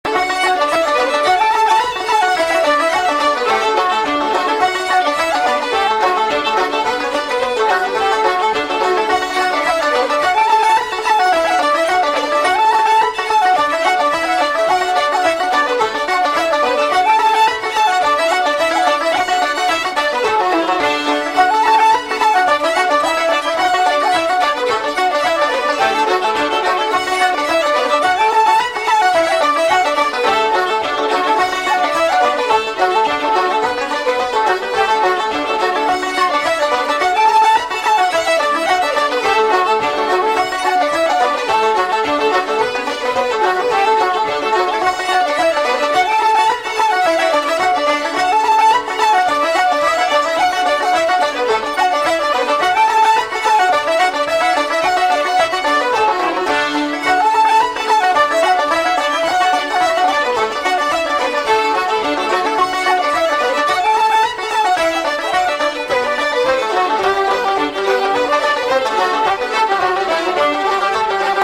John Brennan, The reel Set